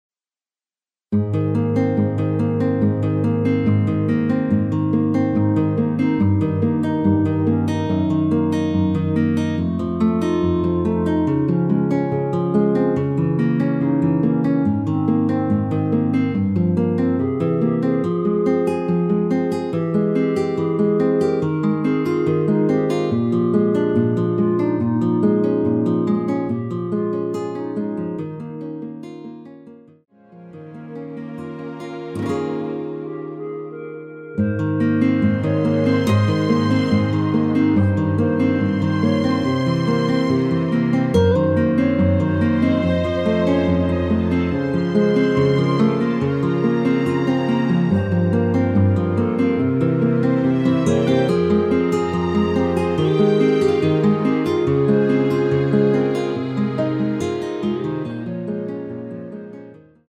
(-1) 멜로디 포함된 MR 입니다.
Ab
◈ 곡명 옆 (-1)은 반음 내림, (+1)은 반음 올림 입니다.
멜로디 MR버전이라서 좋아요
앞부분30초, 뒷부분30초씩 편집해서 올려 드리고 있습니다.
위처럼 미리듣기를 만들어서 그렇습니다.